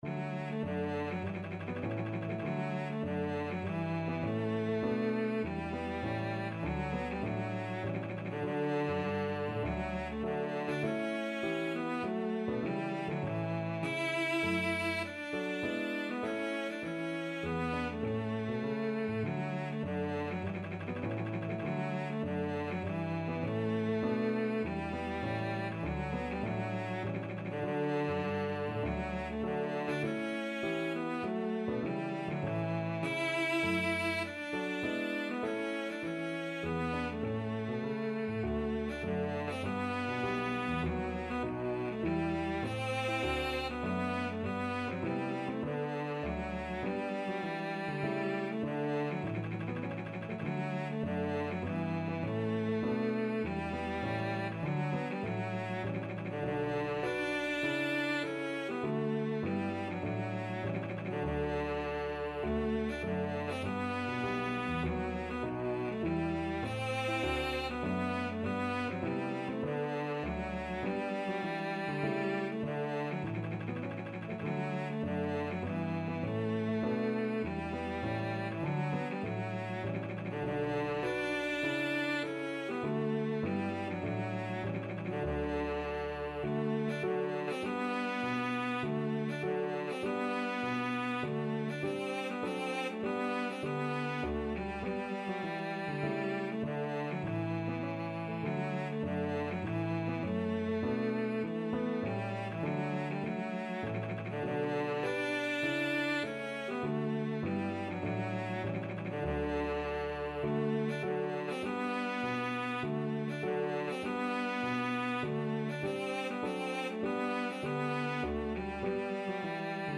Cello
4/4 (View more 4/4 Music)
D major (Sounding Pitch) (View more D major Music for Cello )
Classical (View more Classical Cello Music)